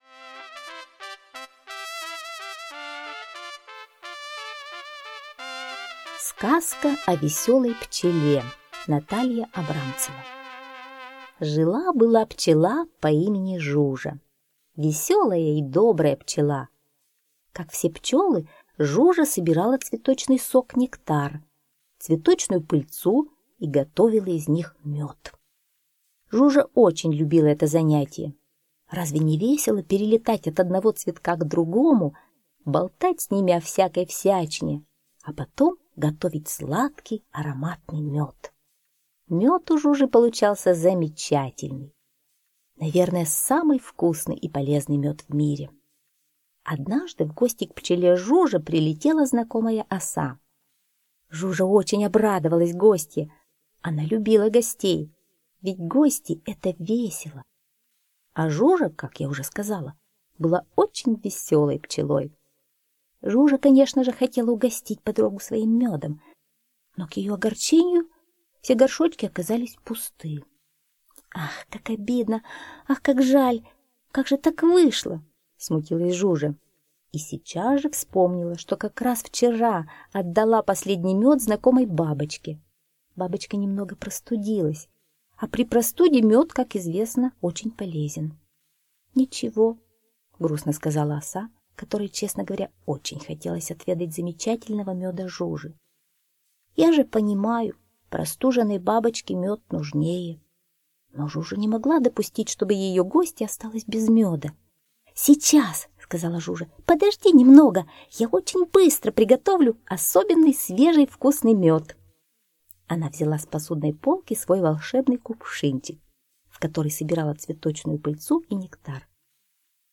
Слушайте Сказка о веселой пчеле - аудиосказка Абрамцевой Н. Сказка про веселую пчелку Жужу, которая делала вкусный мед и всех им угощала.